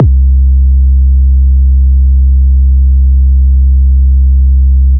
C-EDMBass-2.wav